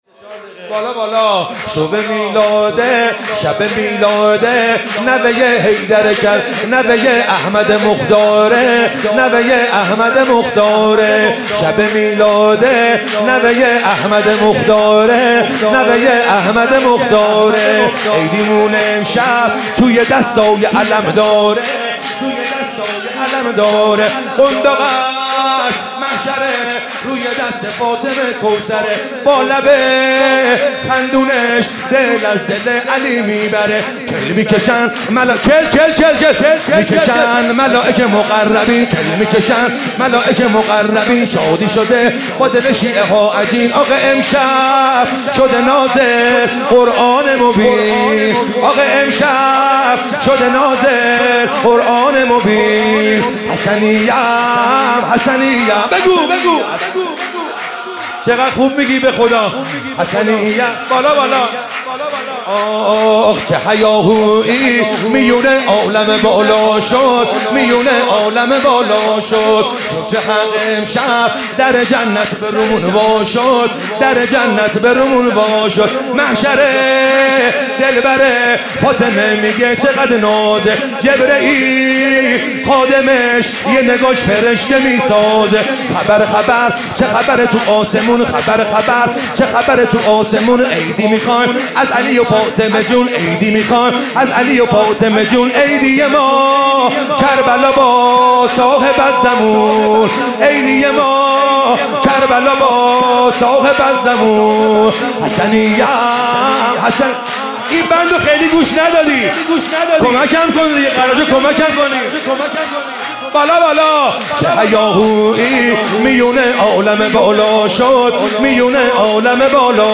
شب شانزدهم ماه مبارک رمضان
مدح